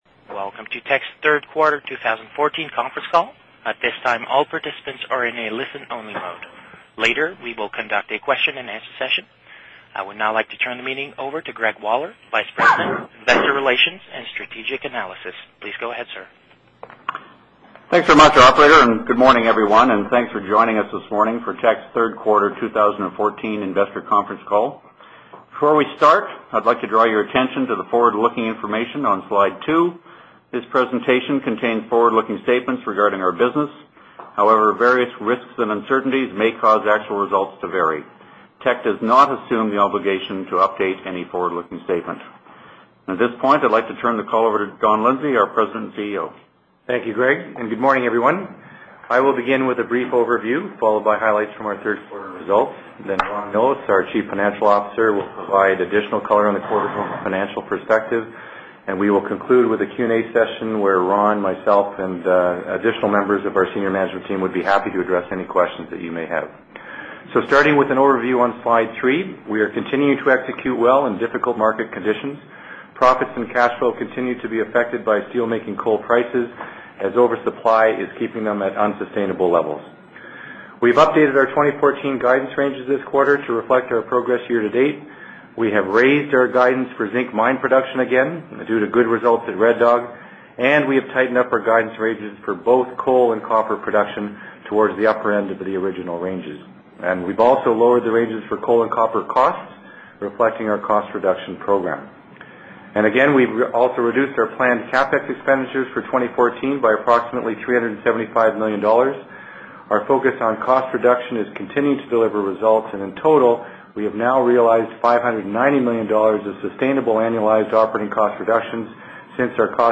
Q3 2014 Financial Report Conference Call Audio File